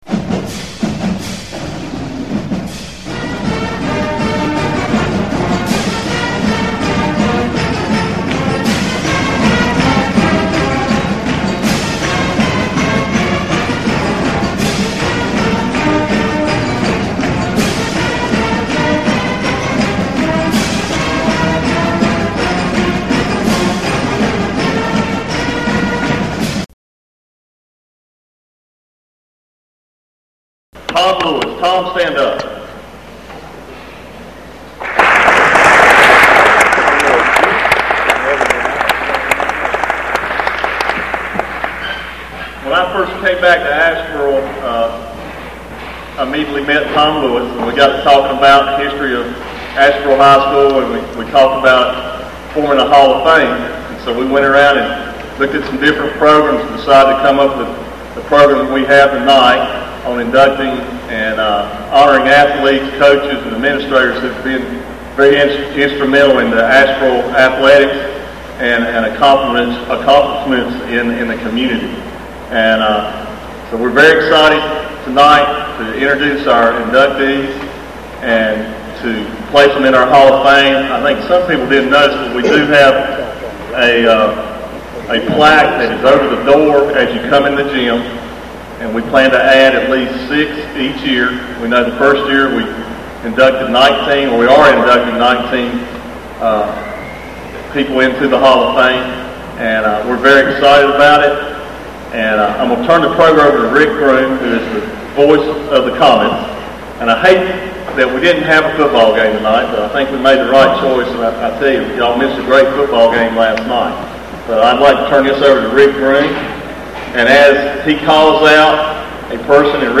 For full Sound of ceremony, no photos